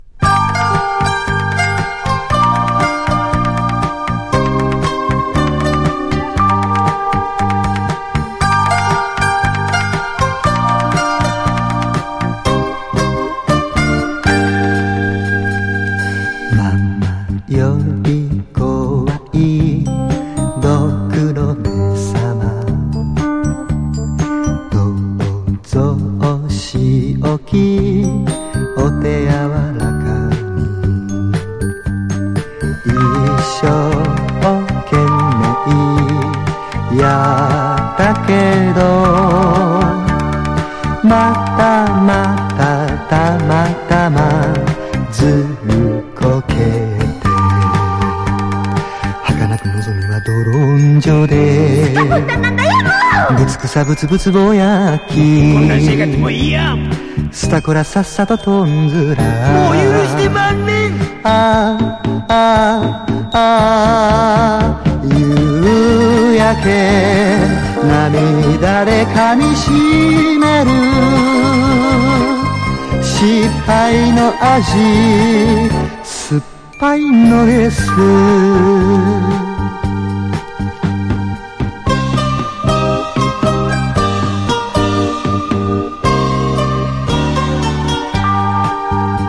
A面曲は下っ端の悪役の気持ちをシブい曲調で歌うノベルティソング。
POP